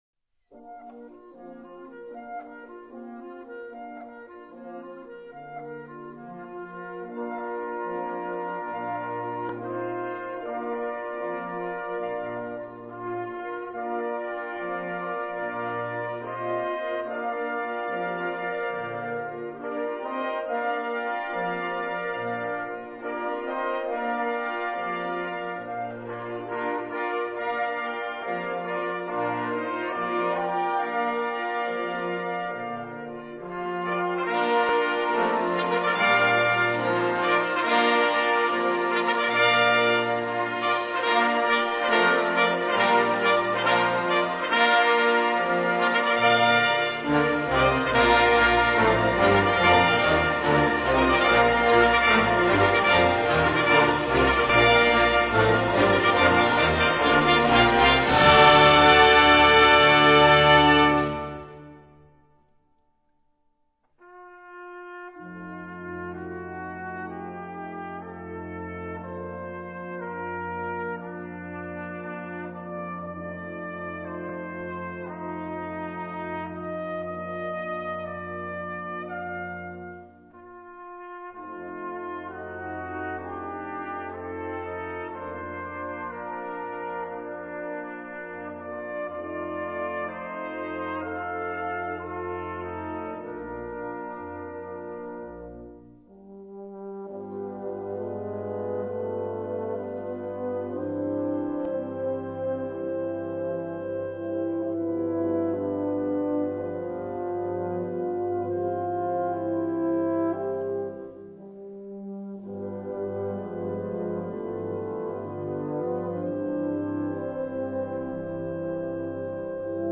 Gattung: Tongemälde
A4 Besetzung: Blasorchester PDF